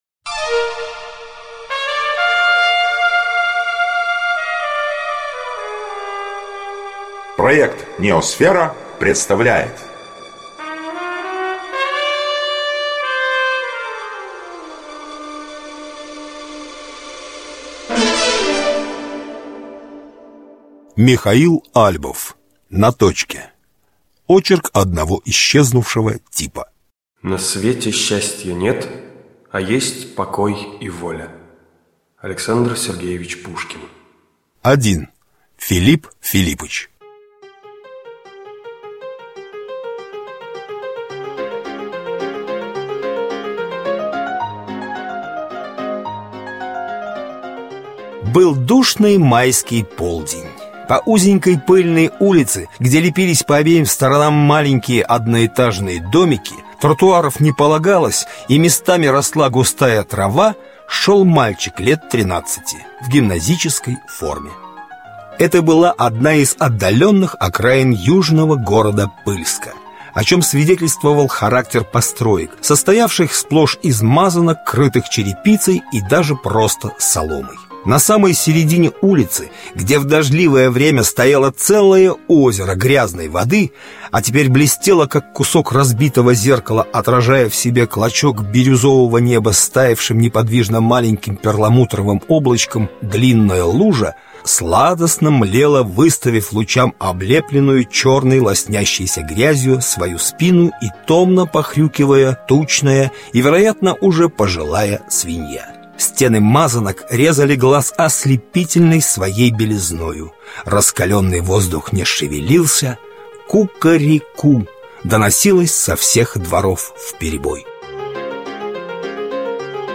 Aудиокнига На точке Автор Михаил Альбов Читает аудиокнигу NEOСФЕРА.